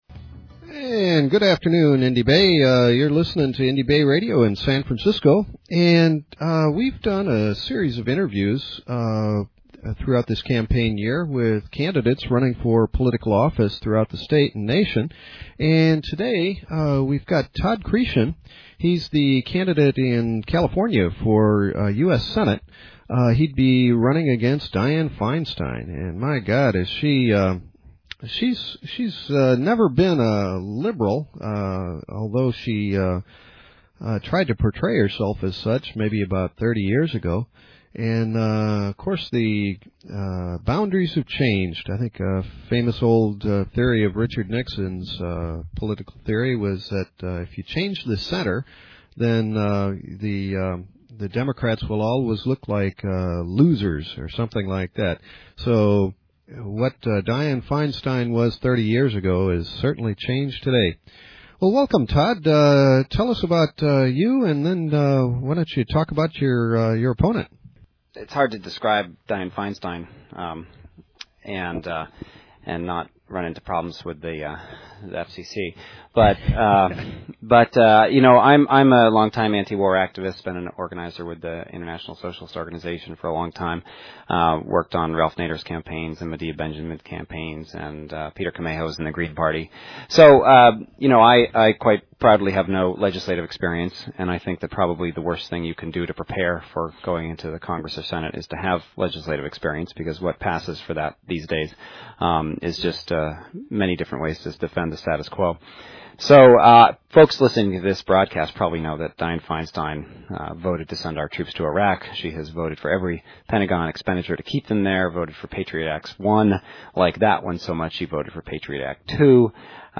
Interview with the Green Party Candidate for US Senate